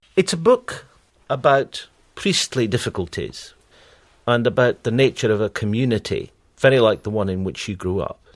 As can be observed, in all the cases above the particle of the phrasal verb, despite being a function element, bears the nucleus of the IP.